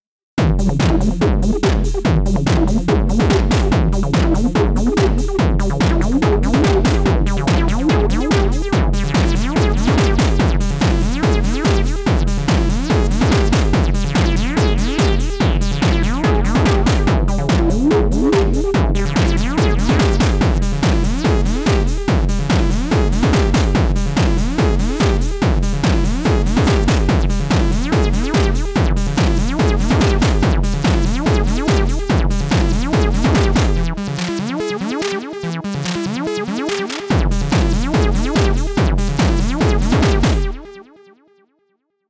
I’ve used 7 SY BITS on this quick&dirty jam.